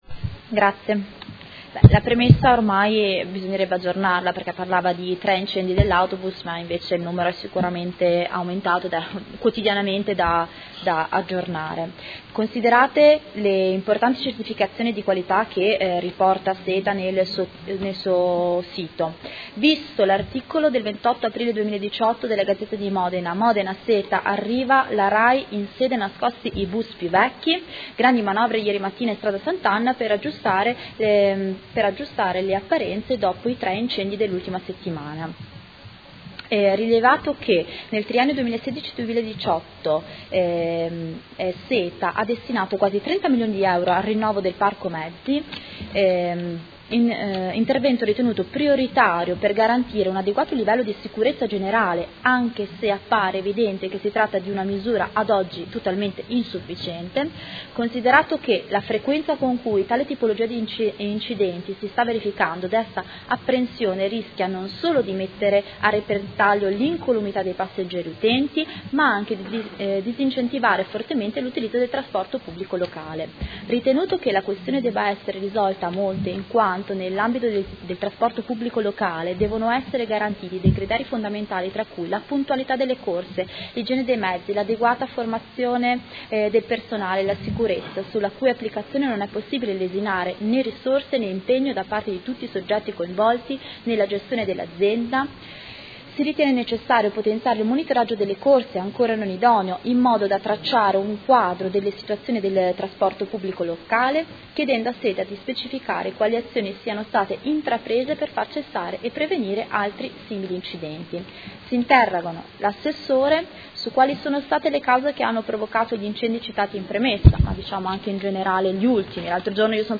Seduta del 21/06/2018 Interrogazione del Gruppo M5S avente per oggetto: Sicurezza mezzi SETA